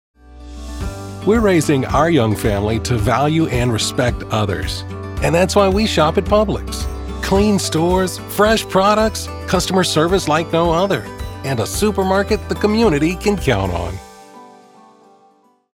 Pubilix - Warm, Friendly, Reassuring